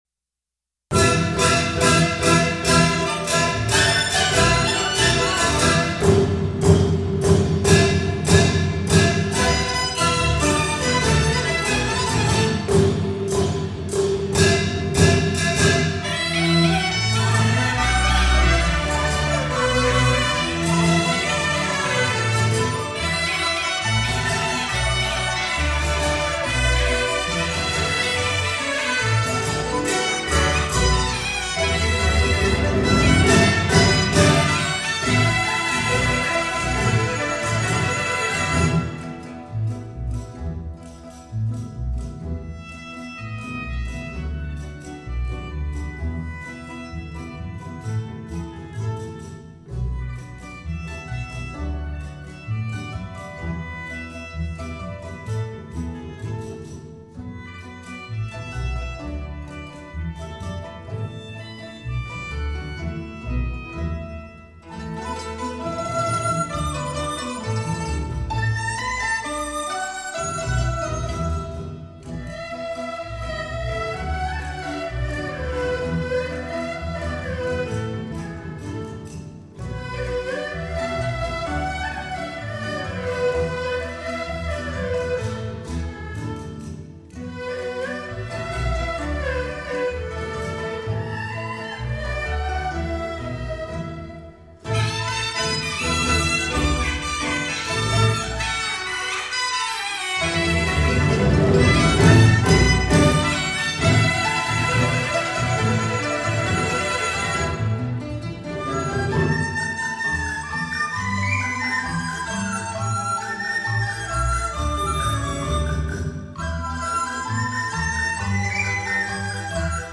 最受歡迎的賀歲喜慶名曲